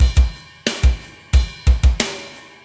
OVERDRIVE MUSIC - Boucle de Batteries - Drum Loops - Le meilleur des métronomes
METAL
Half time - Ride
Straight / 180 / 1 mes